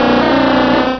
pokeemmo / sound / direct_sound_samples / cries / dewgong.wav